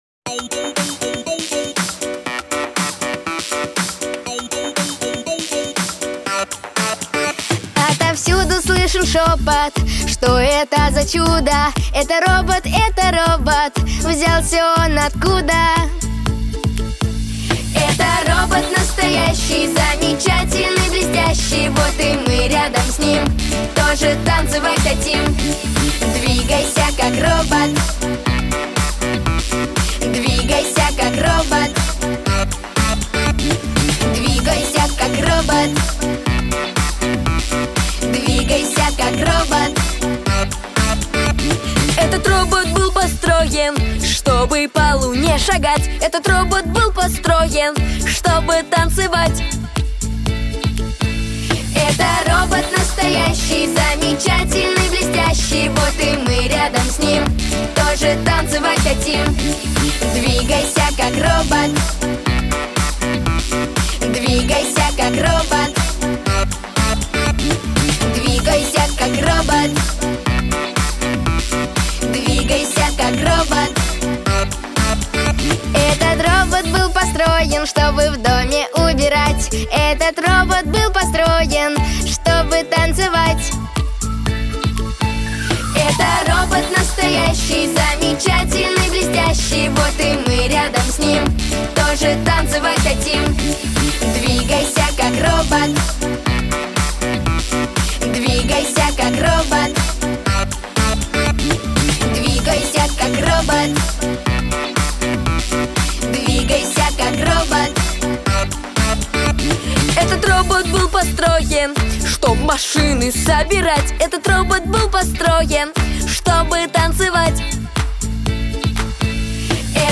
Песни из мультфильмов